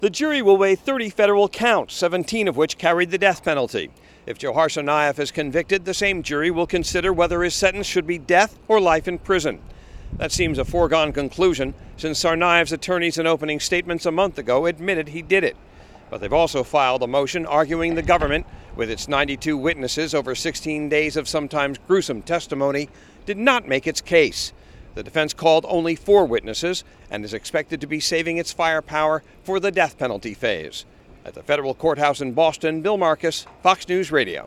REPORTS FROM THE FEDERAL COURTHOUSE IN BOSTON: